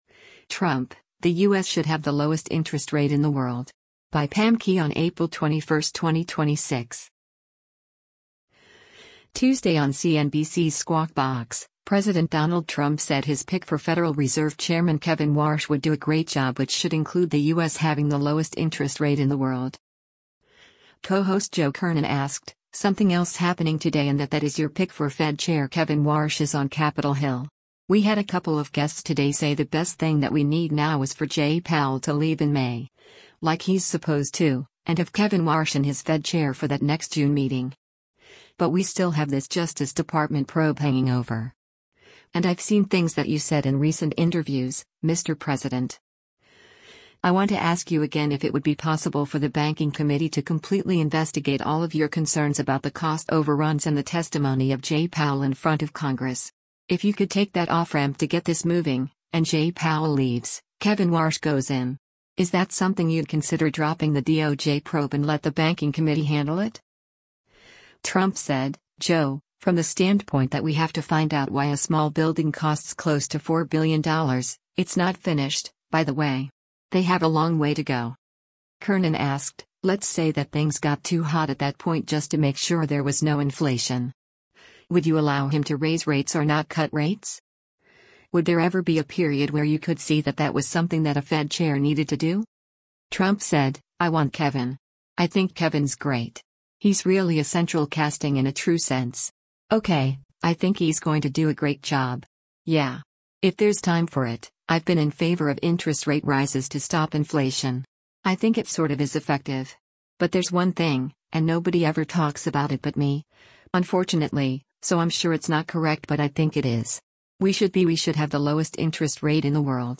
Tuesday on CNBC’s “Squawk Box,” President Donald Trump said his pick for Federal Reserve Chairman Kevin Warsh would do a “great job” which should include the U.S. having the lowest interest rate in the world.